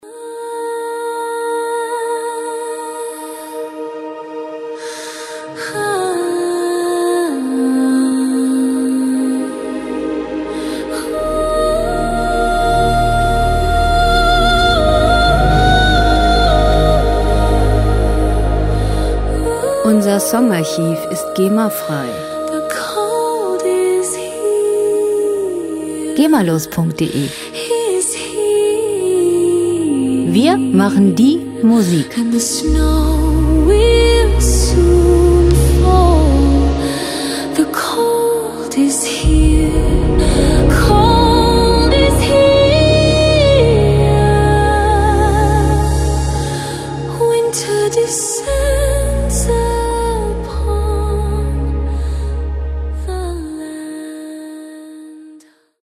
Filmmusik - Märchenreich
Musikstil: Ethereal Wave
Tempo: 60 bpm
Tonart: Es-Moll
Charakter: traumhaft, mystisch
Instrumentierung: Sopran, Orchester